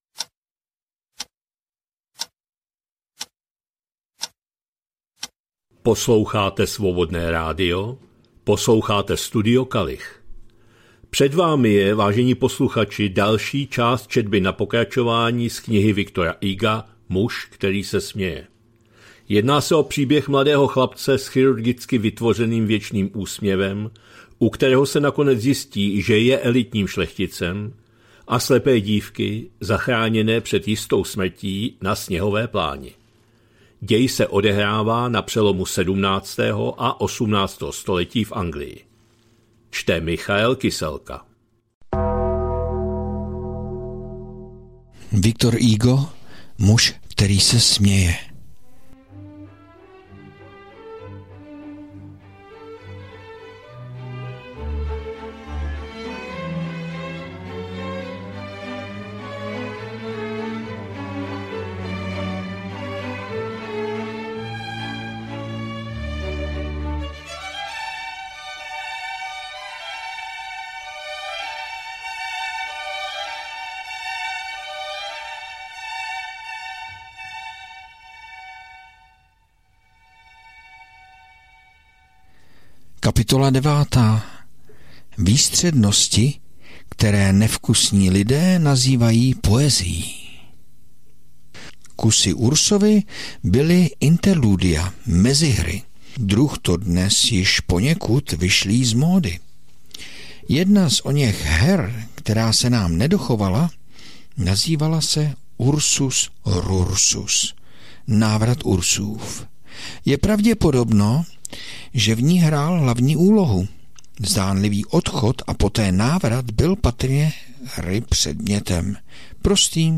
2025-10-06 - Studio Kalich - Muž který se směje, V. Hugo, část 29., četba na pokračování